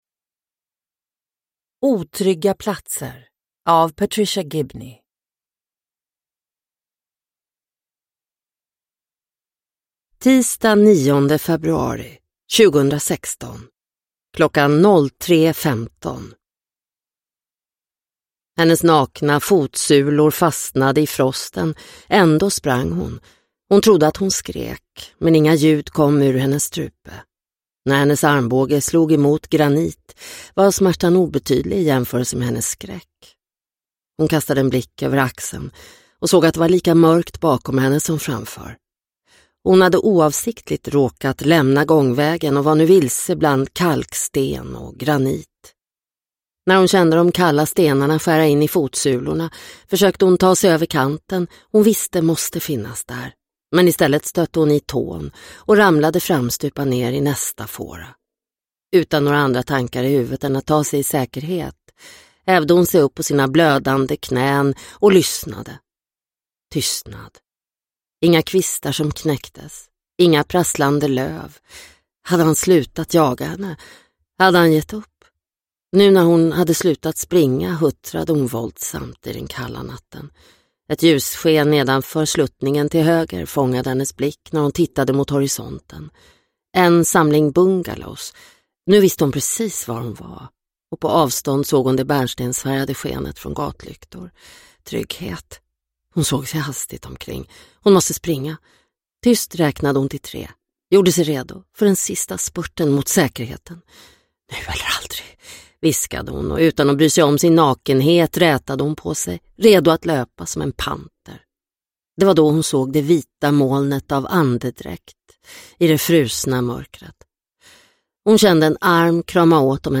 Otrygga platser – Ljudbok – Laddas ner